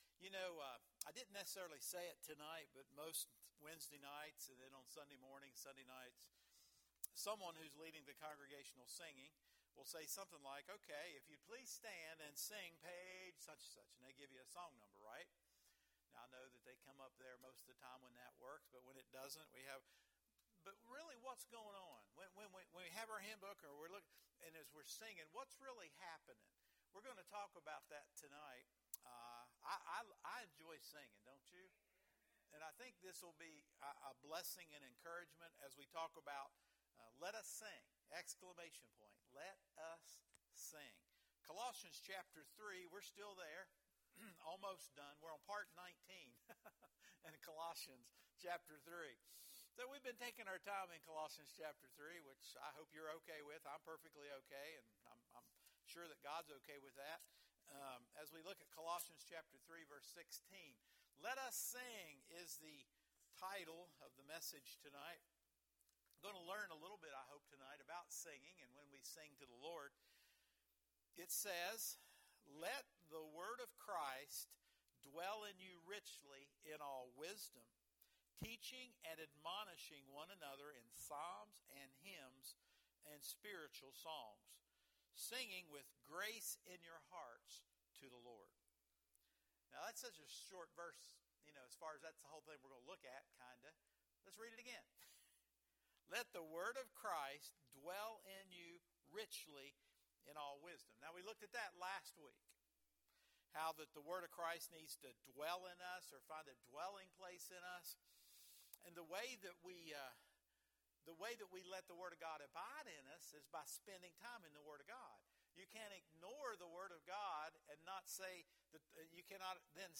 Sermons | Oak Mound Evangelical Church